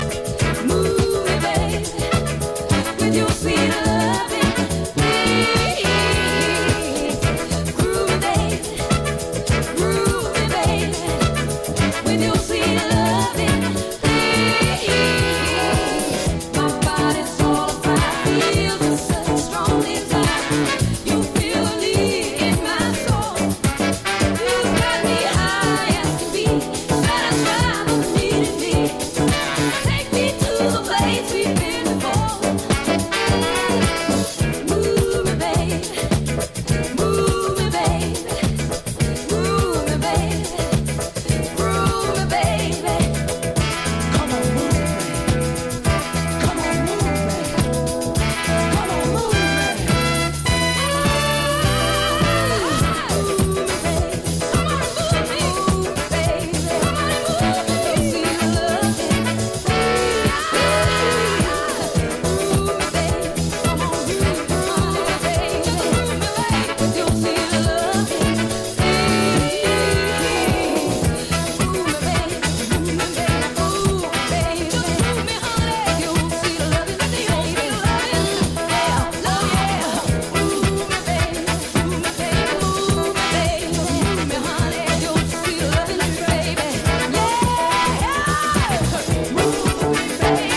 [ SOUL | FUNK ]